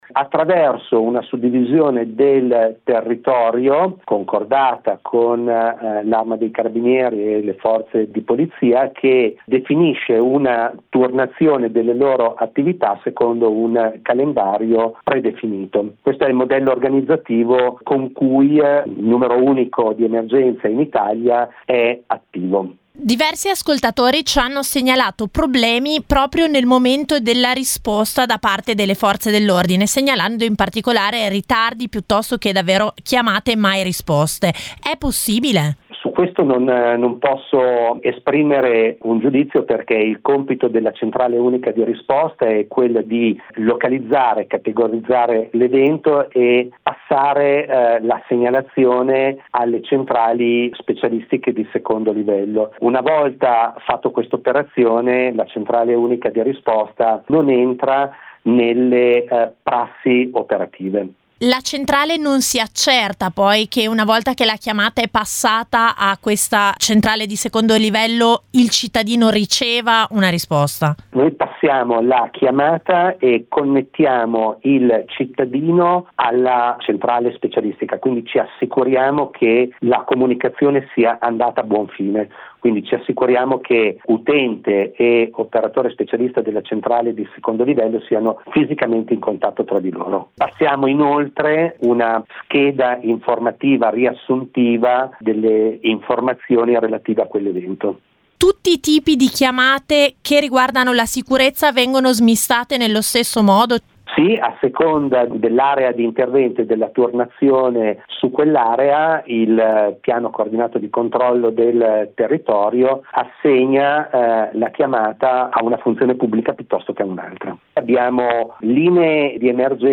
ne ha parlato con